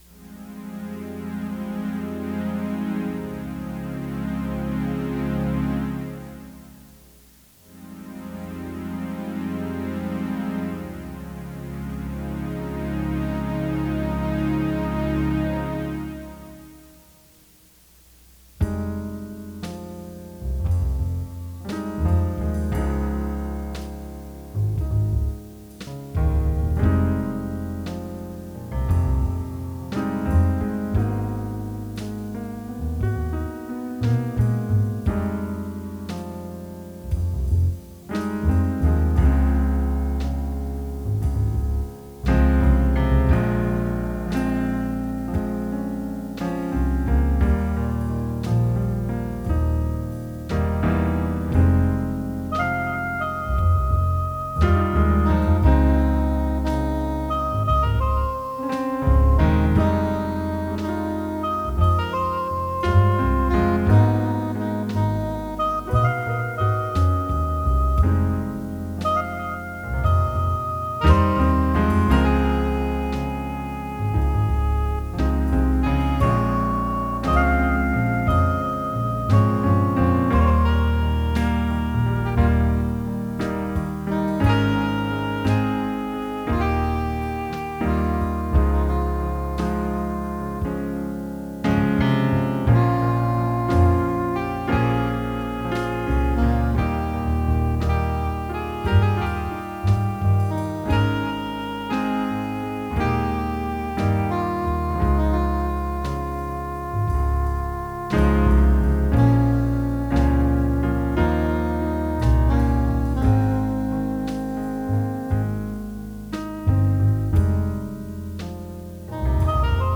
Mostly done with the M1 and SC-88.
I changed it to 6/8 (for reasons?)